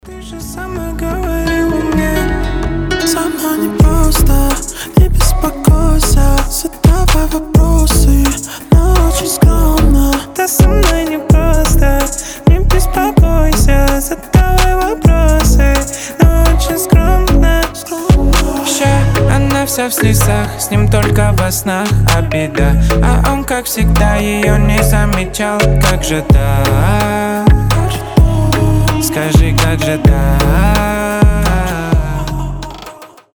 • Качество: 320, Stereo
дуэт